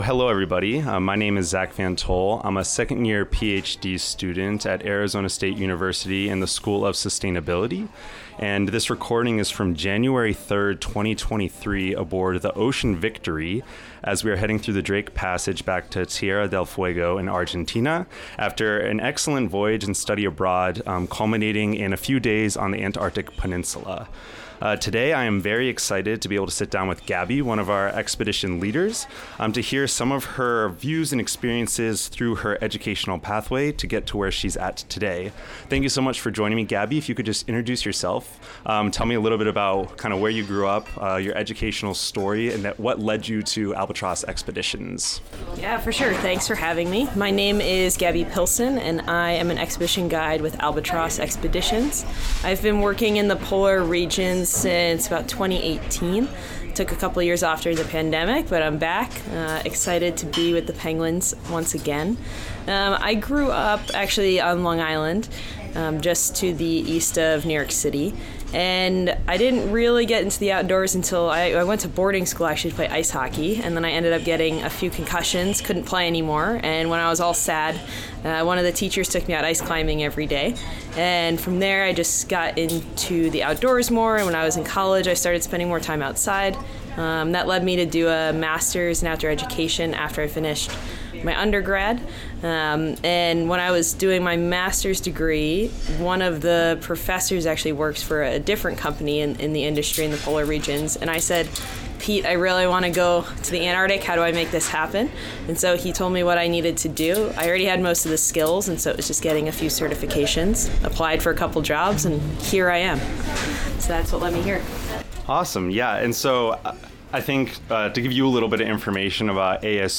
An interview
This podcast took place aboard the Ocean Victory on January 3, 2023, during an Arizona State University study abroad trip to Antarctica.